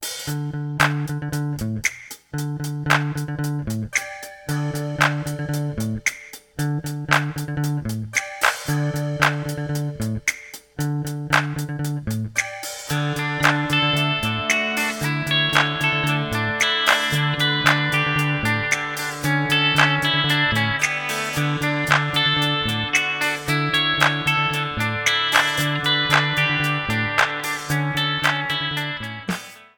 Guitare Basse Tablatures